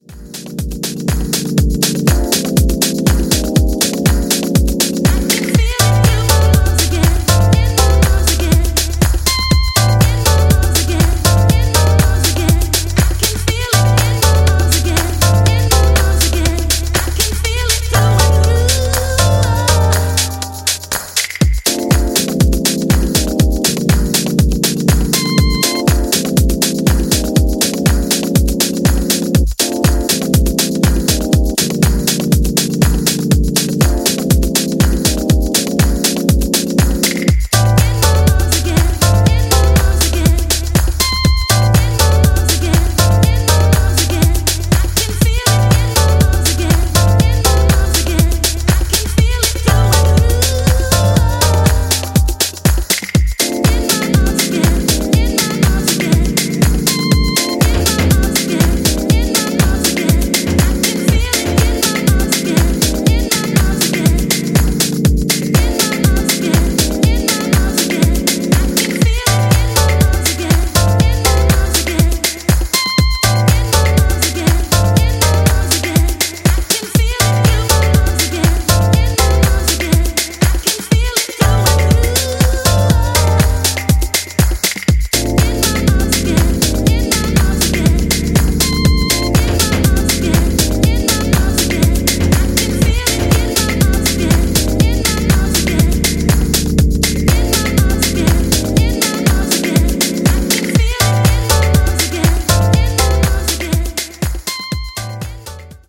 Deep house
Detroit techno